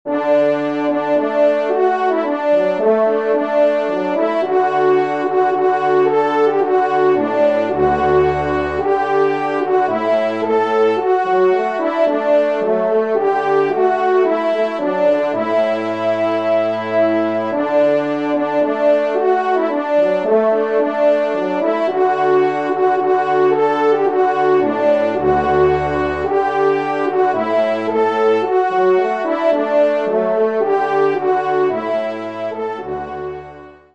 Genre : Divertissement pour Trompes ou Cors
Trompe 1  (en exergue)           Trompe 2  (en exergue)